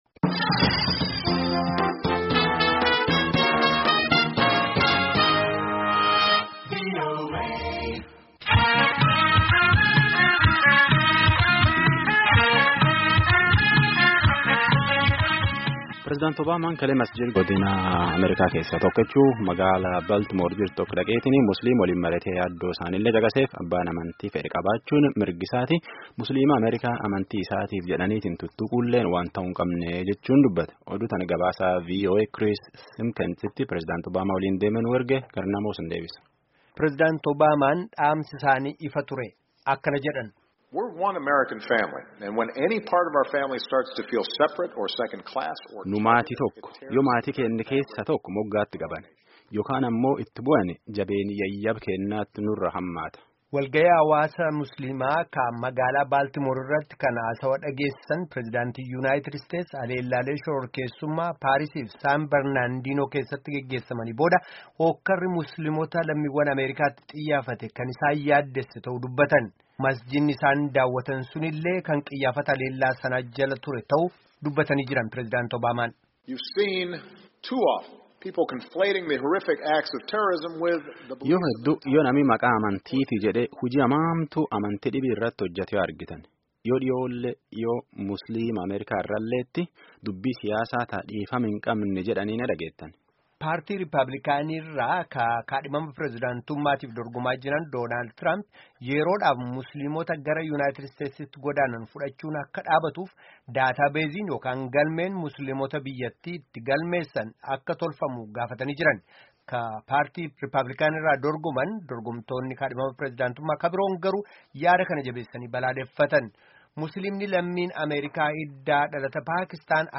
Gabaasni sagalee kunooti